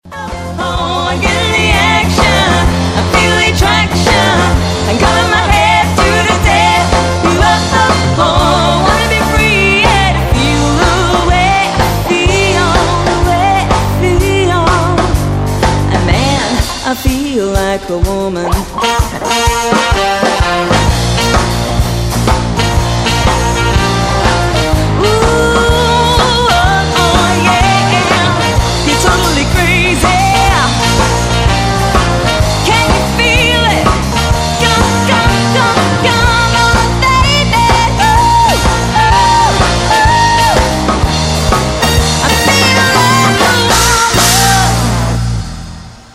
Wedding band soundclips